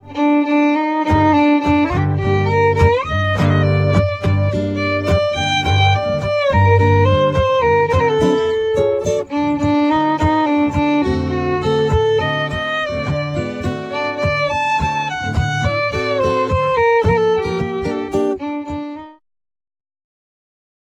A collection of Latin American tunes:
tango